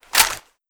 Index of /fastdl/sound/weapons/ak103
draw.wav